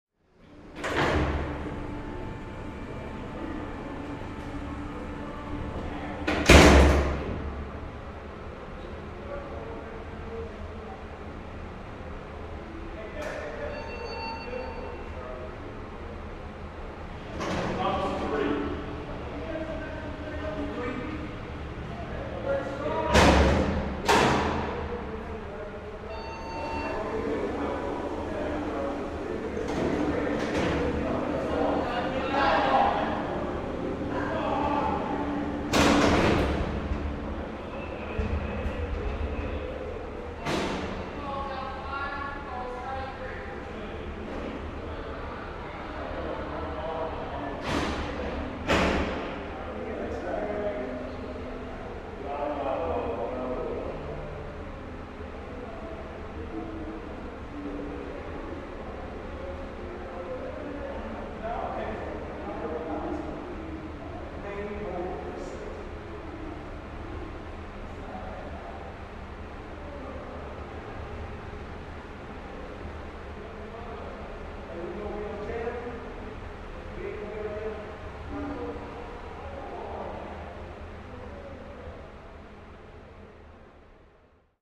На этой странице собраны звуки тюрьмы — от хлопающих дверей камер до приглушенных разговоров в коридорах.
Атмосферный гул тюремных стен